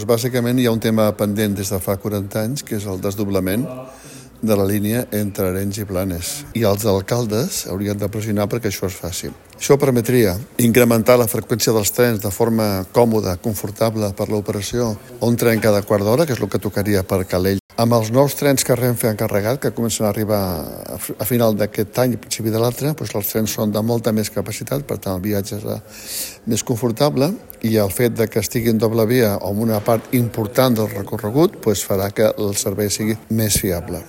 Acte de campanya de Comuns Sumar a Calella, amb propostes per millorar el servei del tren a la comarca.